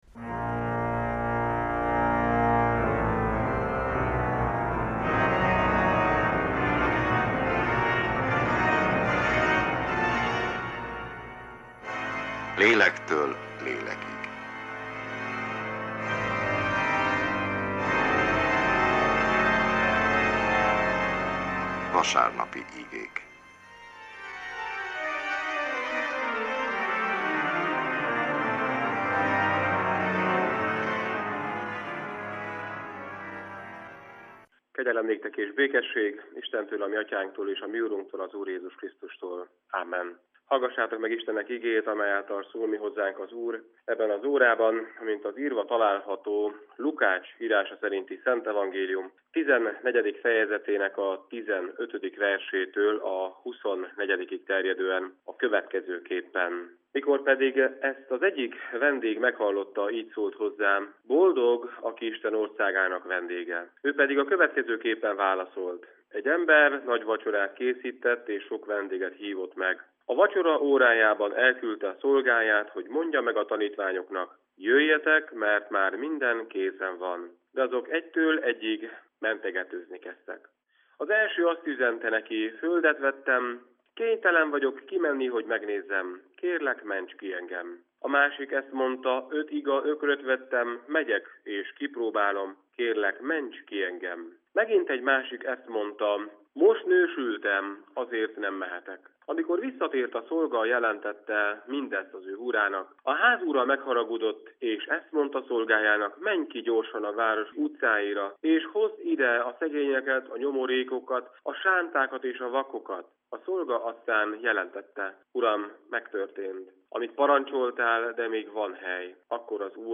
Evangélikus igehirdetés, március 6.
Egyházi műsor